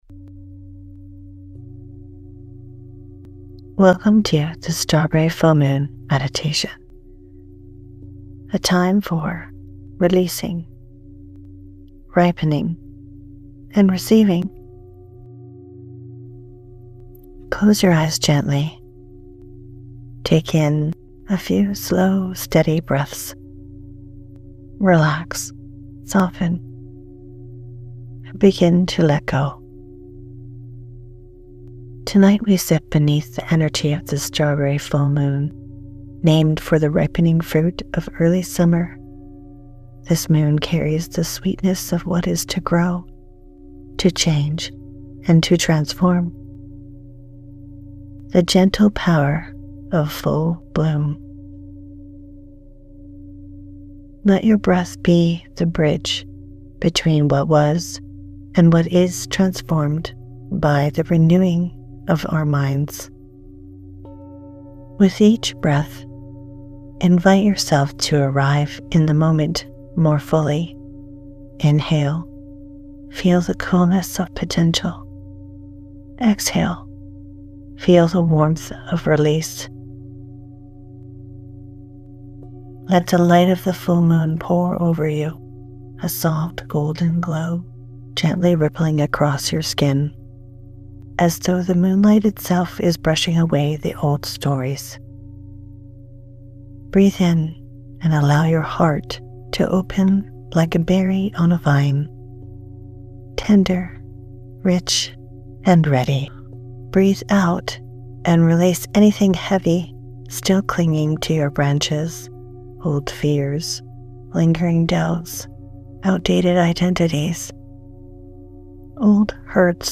Background Music: “Buddha Bells” by Xumantra